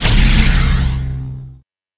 Giving tesla some TLC made me decide to spruce up the prisms to balance their acoustic presence.
made prism tank shot fit more with the prism tower shot thematically